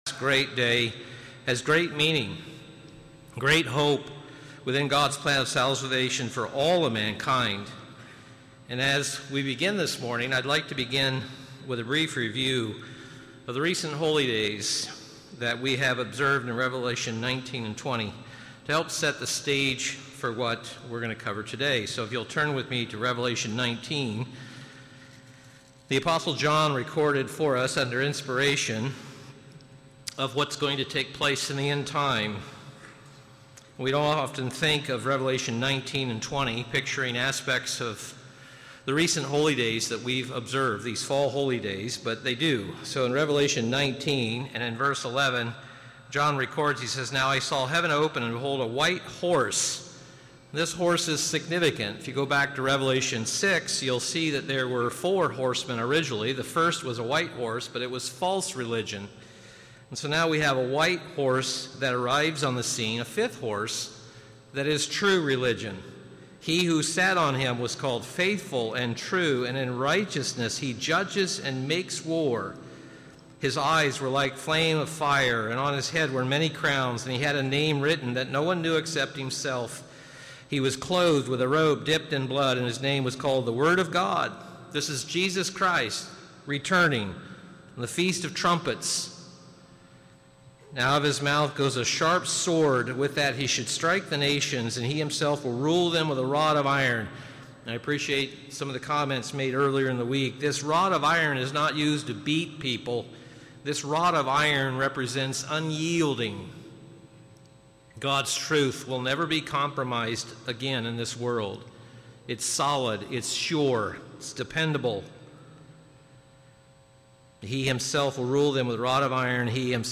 This sermon was given at the Daytona Beach, Florida 2021 Feast site.